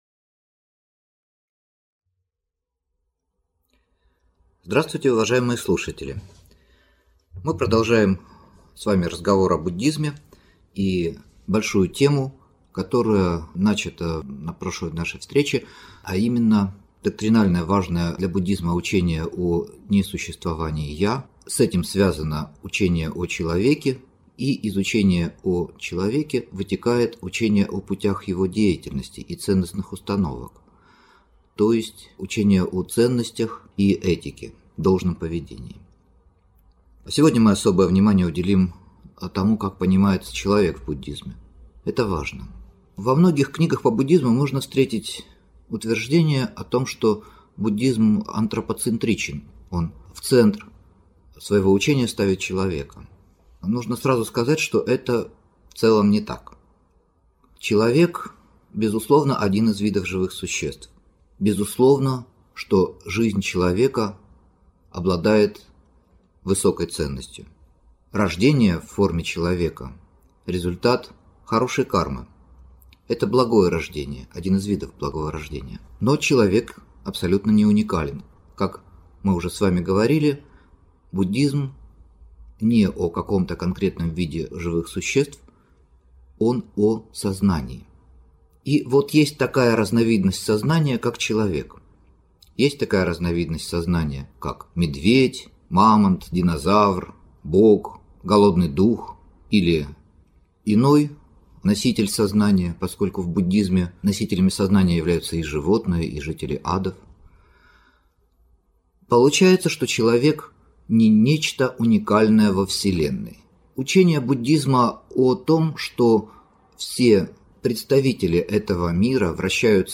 Аудиокнига Учение о человеке в буддизме | Библиотека аудиокниг